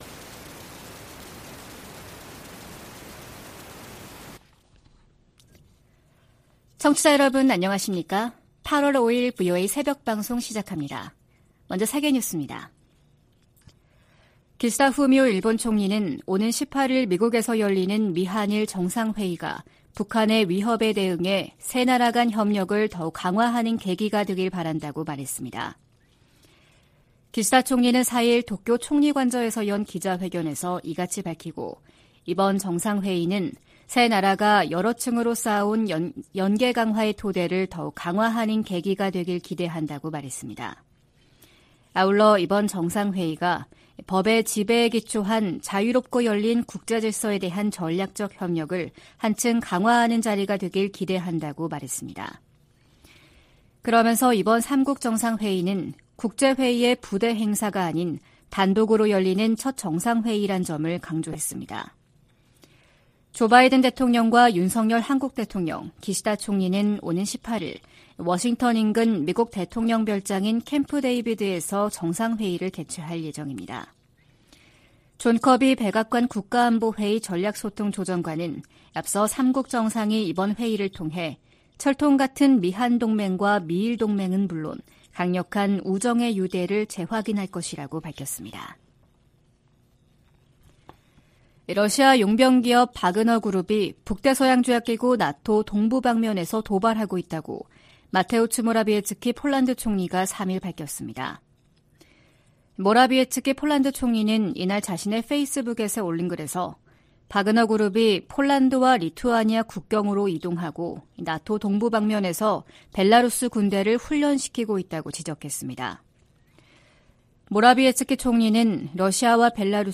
VOA 한국어 '출발 뉴스 쇼', 2023년 8월 5일 방송입니다. 오는 18일 미한일 정상회의에서 북한의 미사일 방어 등 3국 안보 협력을 강화하는 방안이 논의될 것이라고 한국 국가안보실장이 밝혔습니다. 러시아 국방장관의 최근 평양 방문은 군사장비를 계속 획득하기 위한 것이라고 백악관 고위관리가 지적했습니다. 토니 블링컨 미 국무장관은 북한이 월북 미군의 행방과 안위등에 관해 답변을 하지 않았다고 말했습니다.